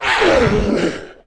Index of /App/sound/monster/misterious_diseased_spear